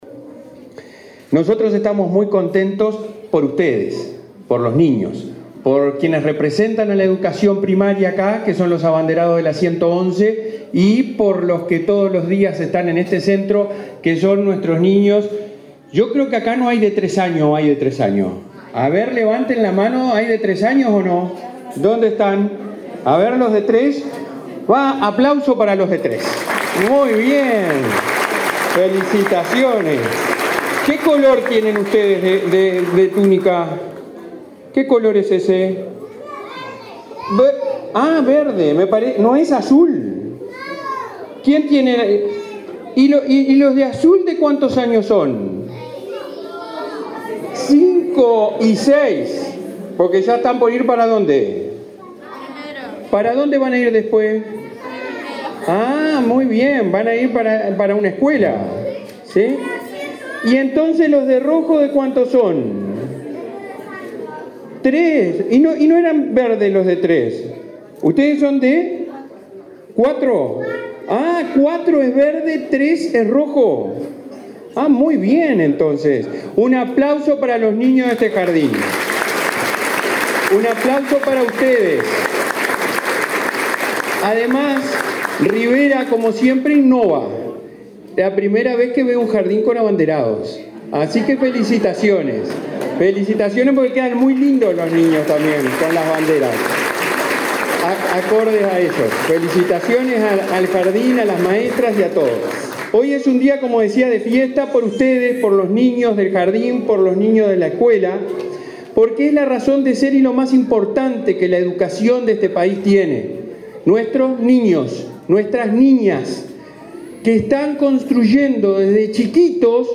Palabras del presidente de ANEP, Robert Silva
Este viernes 26, el presidente del Consejo Directivo Central (Codicen) de la Administración Nacional de Educación Pública (ANEP), Robert Silva,
participó en la inauguración del jardín de infantes n.° 155 en Rivera.